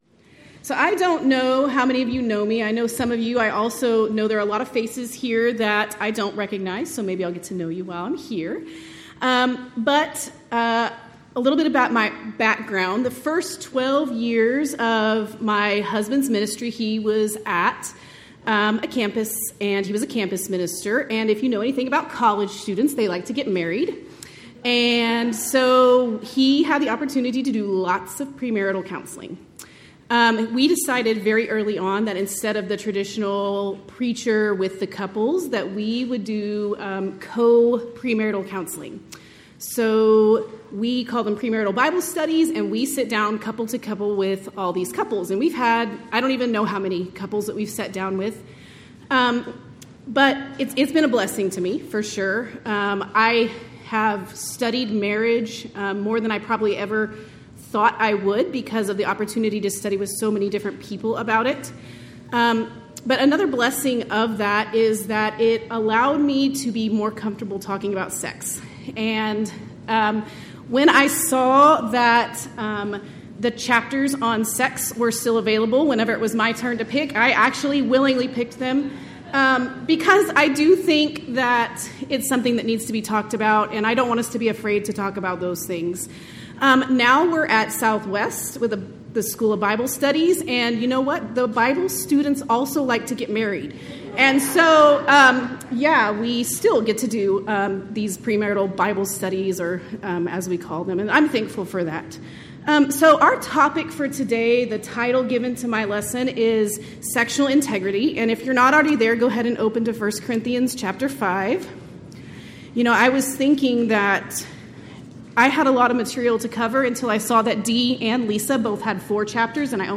Event: 13th Annual Texas Ladies in Christ Retreat Theme/Title: Studies in 1 Corinthians
Ladies Sessions